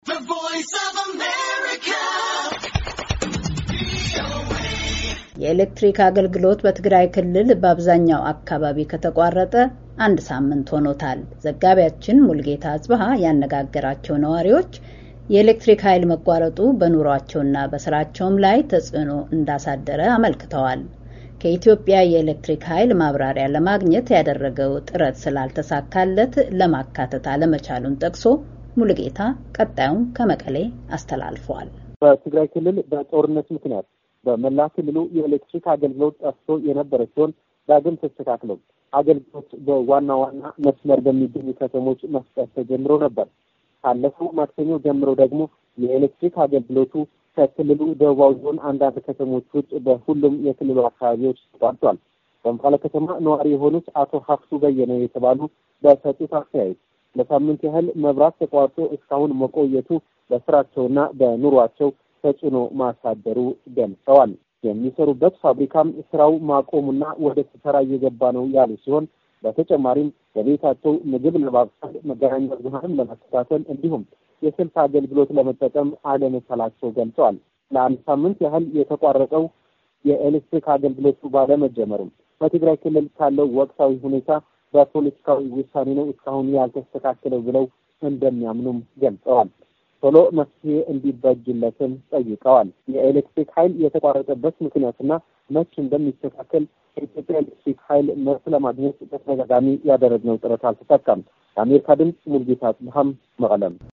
የኤሌክትሪክ አገልግሎት በትግራይ ክልል በአብዛኛው አካባቢ ከተቋረጠ አንድ ሳምንት ሆኖታል። ዘጋቢያችን ያነጋገራችው አንዳንድ ነዋሪዎች፣ የኤሌክትሪክ ኃይል መቋረጡ በኑሯቸውና በሥራቸው ላይ ተጽዕኖ እንዳሳደረ አመልክተዋል።